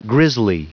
Prononciation du mot grisly en anglais (fichier audio)
Prononciation du mot : grisly